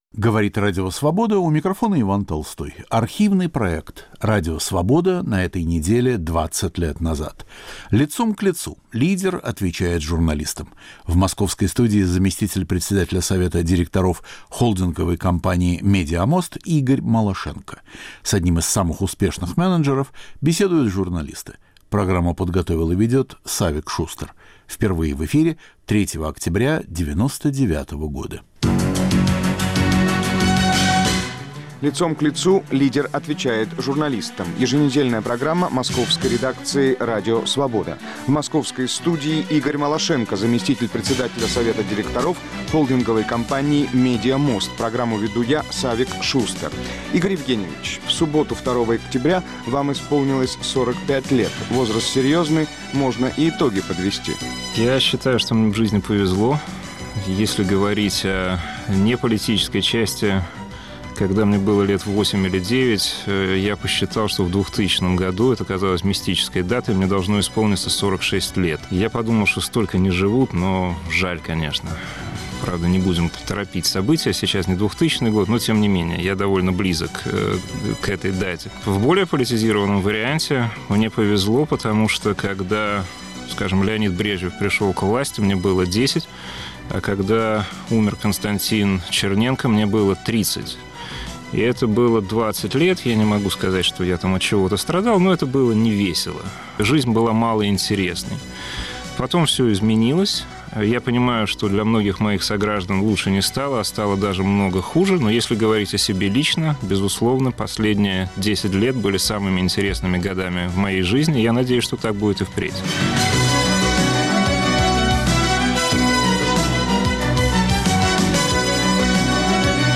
Радио Свобода на этой неделе 20 лет назад. В студии Игорь Малашенко
Архивный проект. Иван Толстой выбирает из нашего эфира по-прежнему актуальное и оказавшееся вечным.